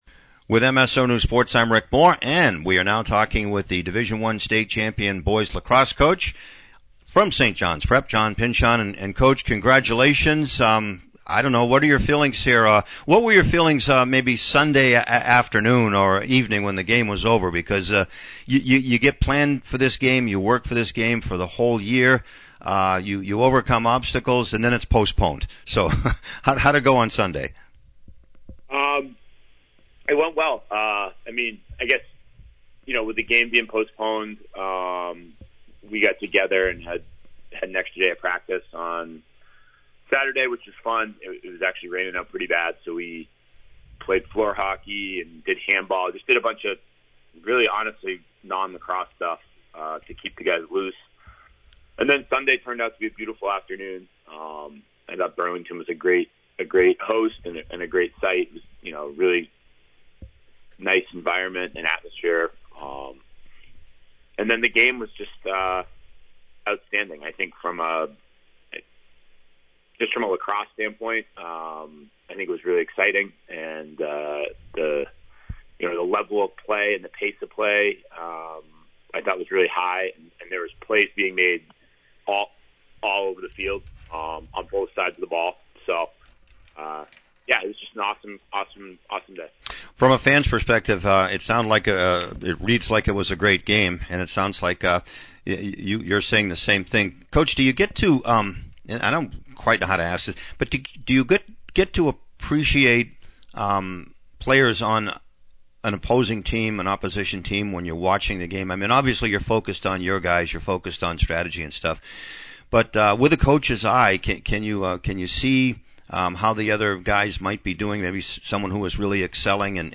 (Audio) Post-game, Post-Tournament, Post-Season